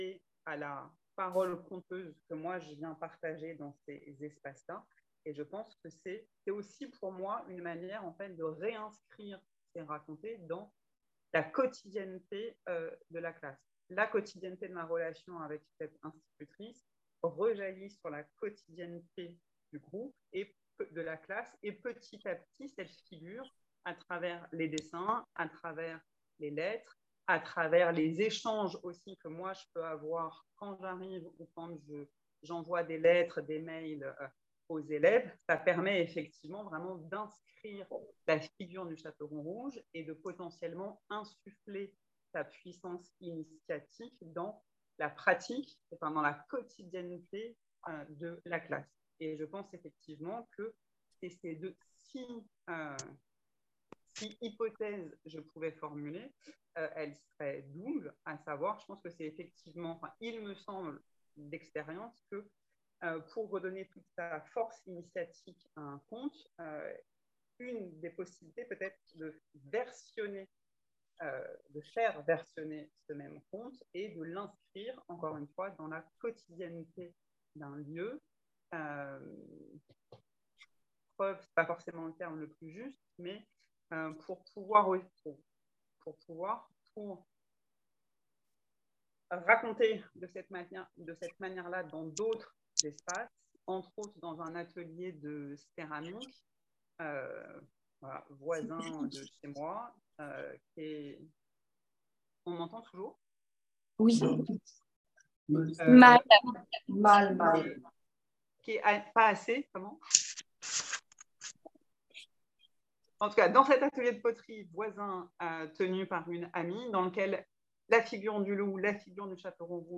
Séminaire 2023 journée 6